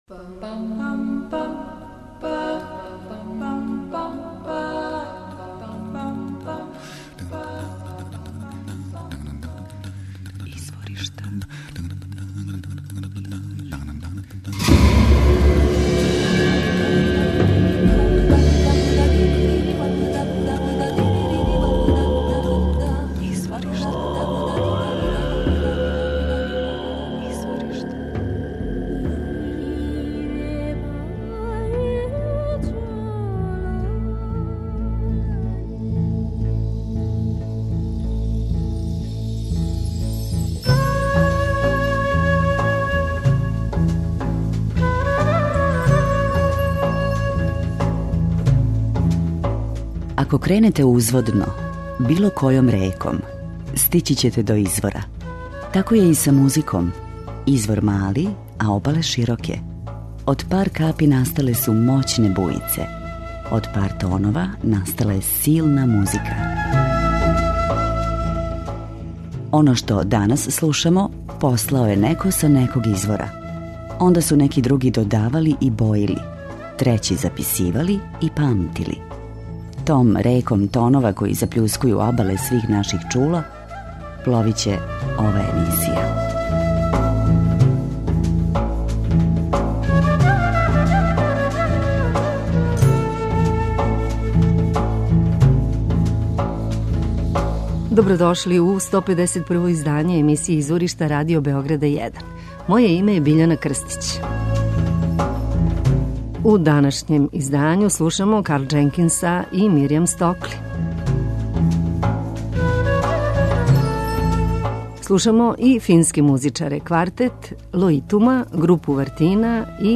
У две речи: World Music.